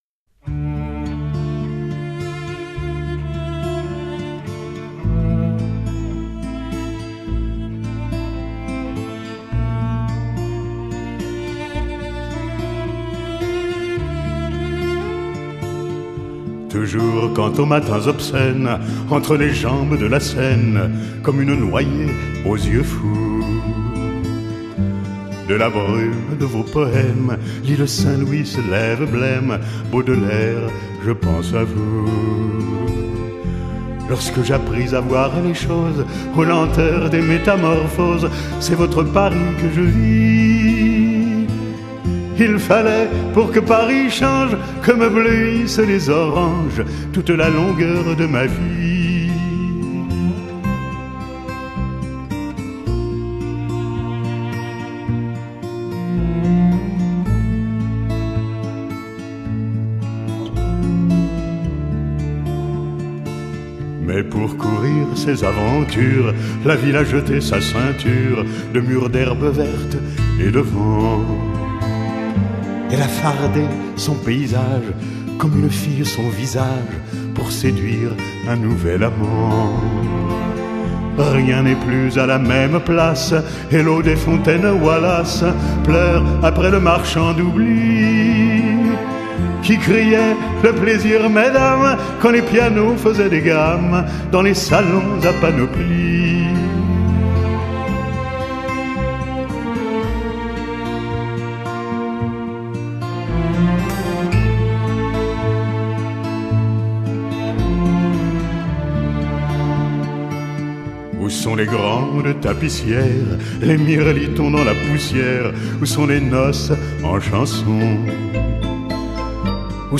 Genre : Chanson